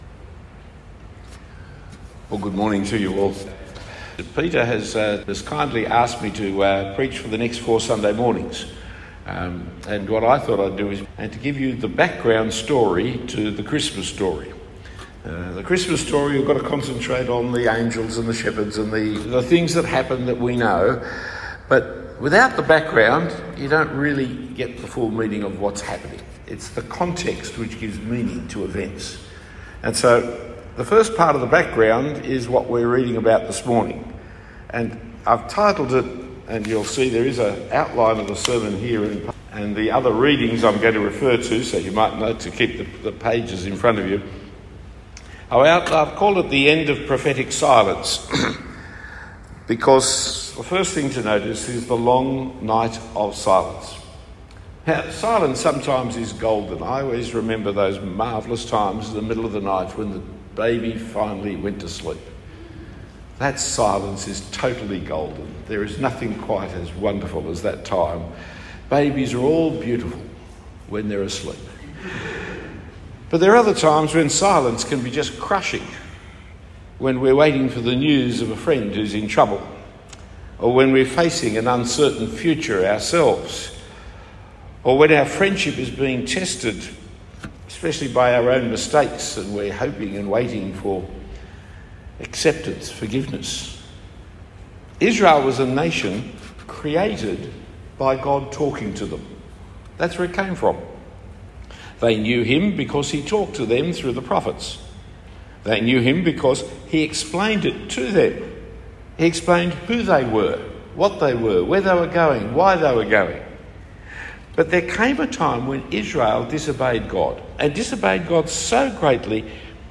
Talk 1 of 4 given in the Christmas period at St Nicolas Coogee.